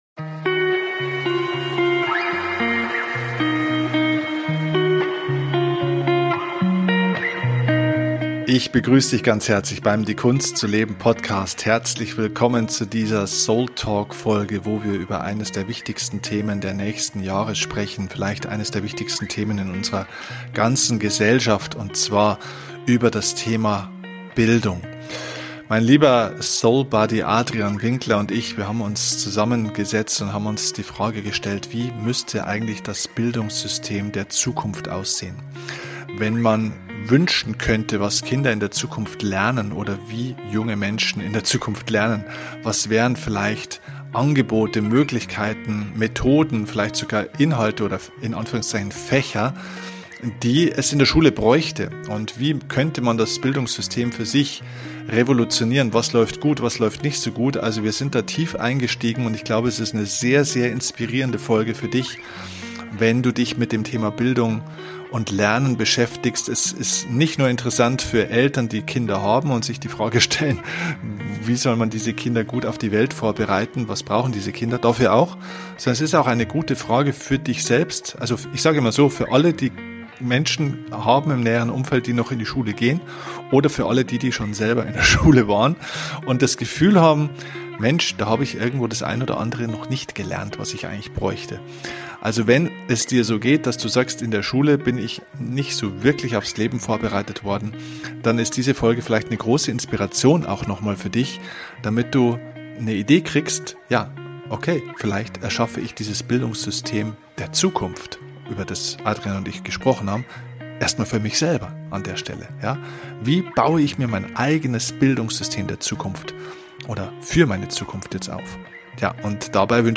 Kein Skript, nur ein Thema über das wir uns unterhalten.